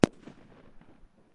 爆炸 " 006 烟花
Tag: 大声 臂架 轰隆 烟花 爆竹 爆炸